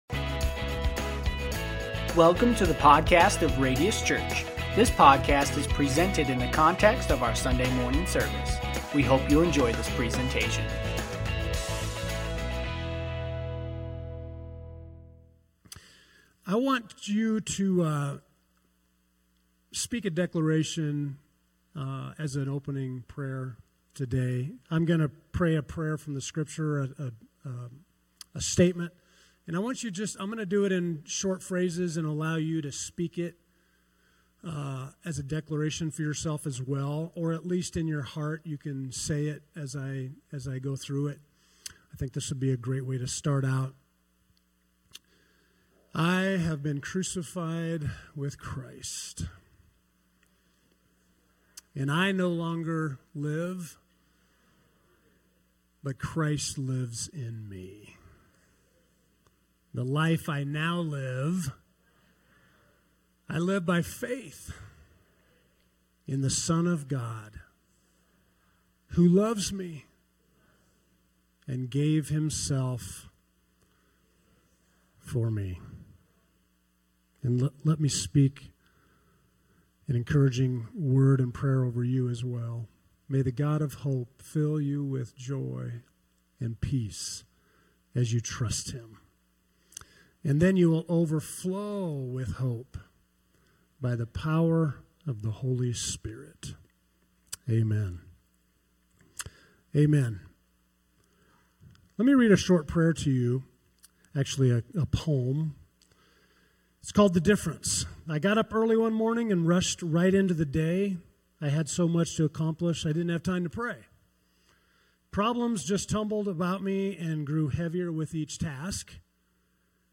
Sermons | Radius Church